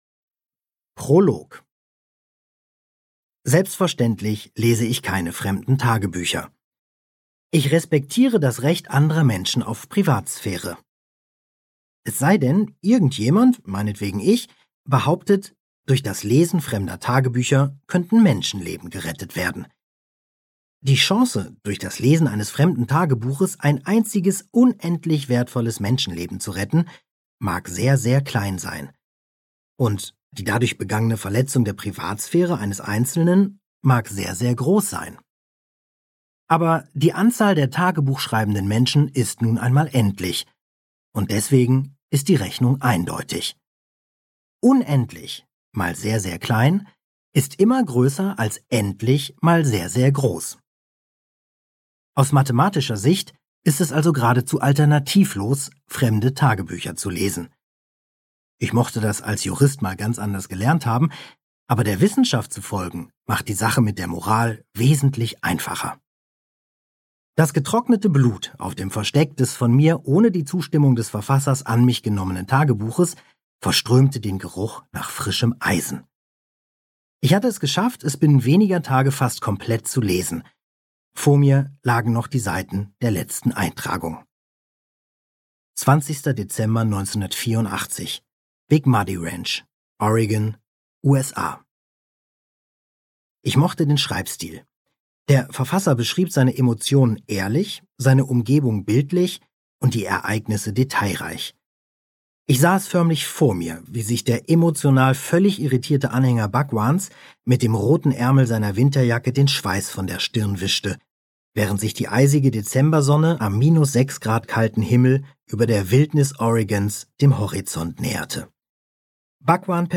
Audio kniha
• InterpretKarsten Dusse